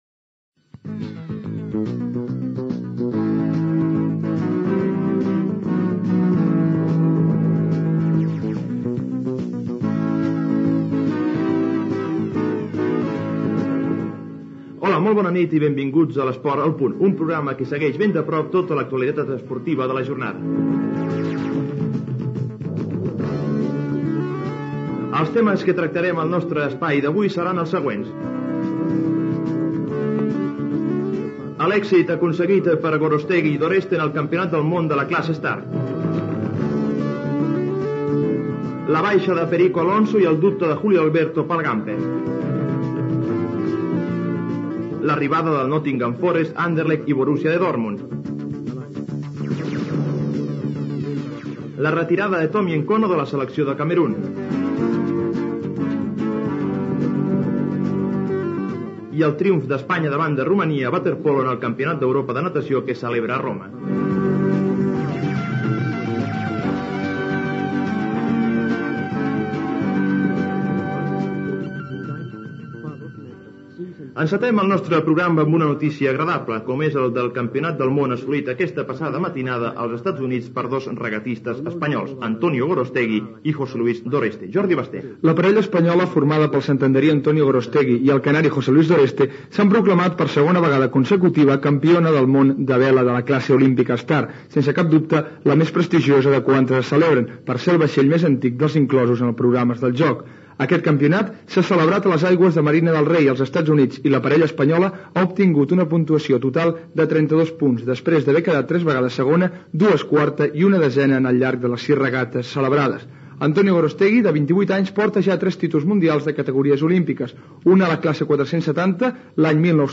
Presentació, titulars, campionat del món de vela, trofeu de futbol Joan Gamper Gènere radiofònic Esportiu